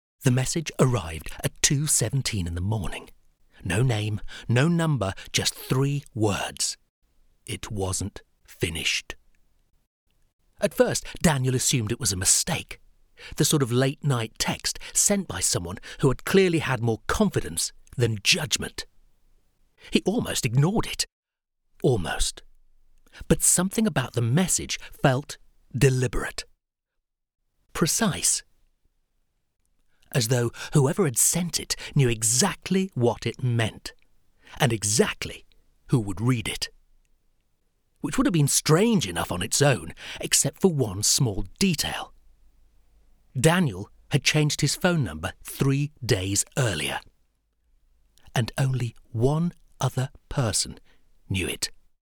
Mystery Drama audiobook narration
Cozy mystery drama storytelling from Audible